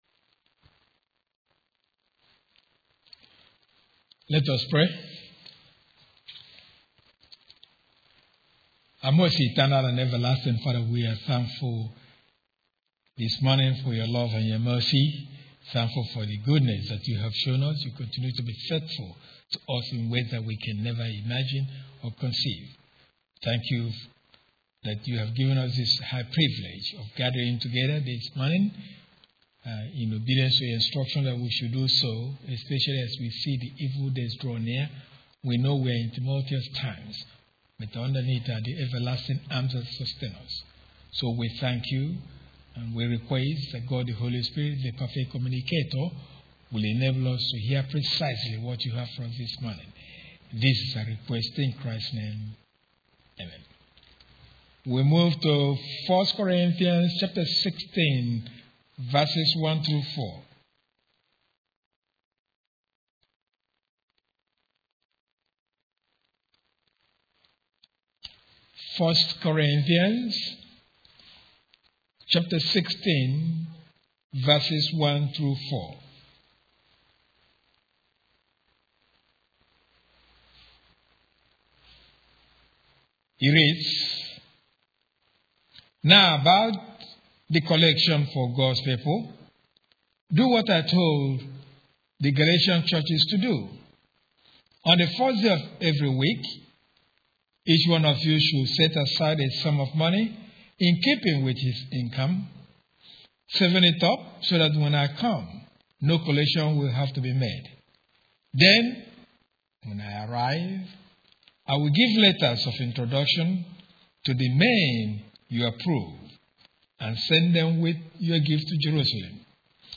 1 Exodus #349 - Jan 1, 2025 1:00:00 Play Pause 20h ago 1:00:00 Play Pause Später Spielen Später Spielen Listen Gefällt mir Geliked 1:00:00 Wednesday Bible Lesson: 19:16-19 Yahweh's Special Appearance on Mount Sinai.